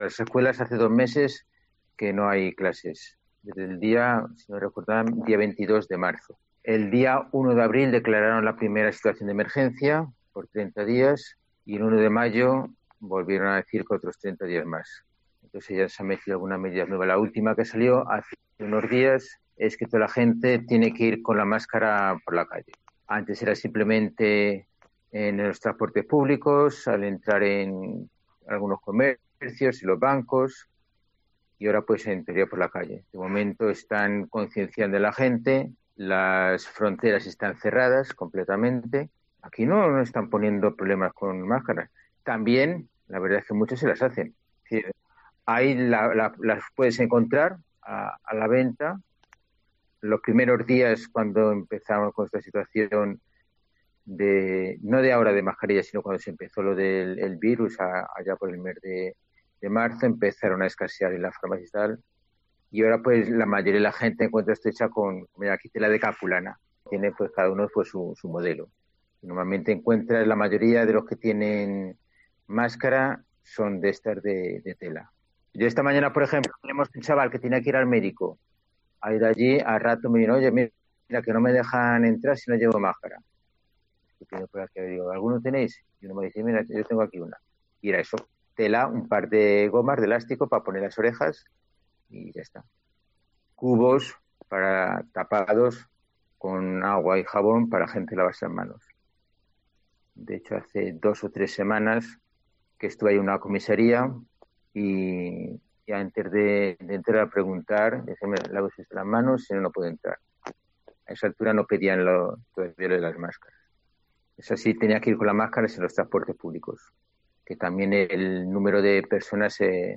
El misionero